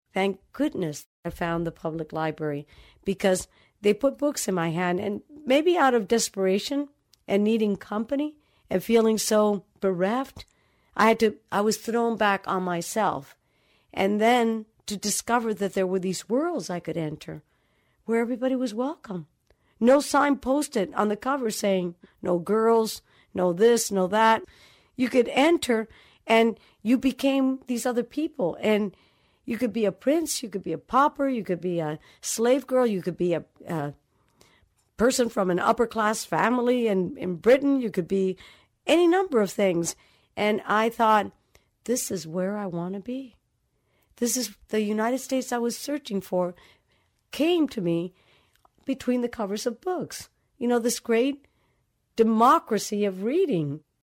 Transcript of conversation with Julia Alvarez